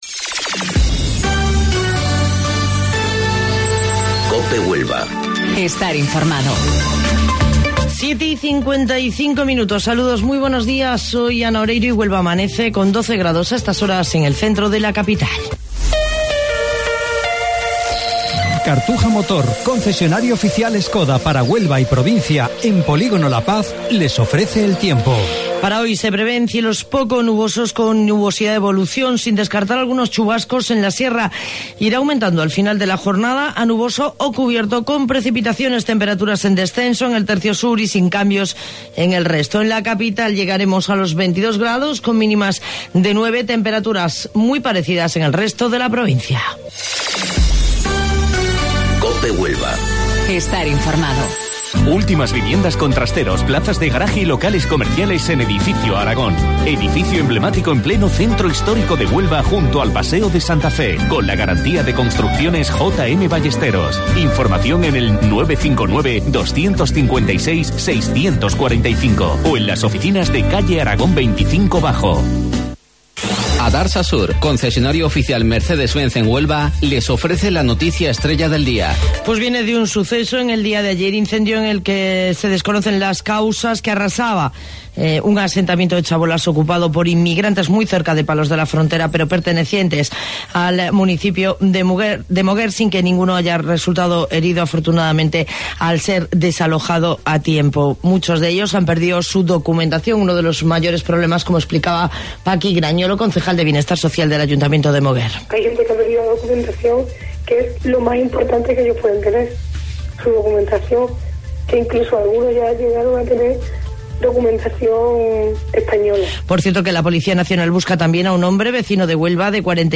AUDIO: Informativo Local 07:55 del 22 de Abril